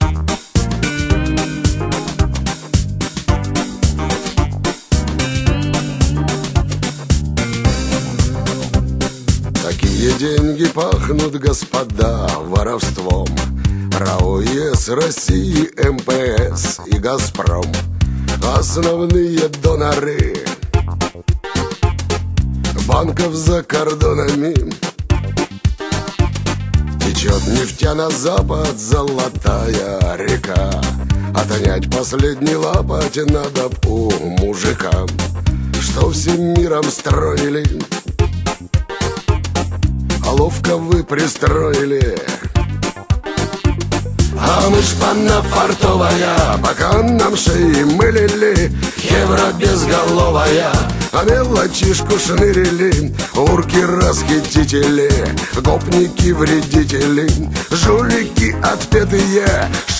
Шансон
мелодичным и хриплым голосом